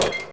1 channel
LADDER5.mp3